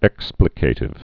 (ĕksplĭ-kātĭv, ĭk-splĭkə-tĭv)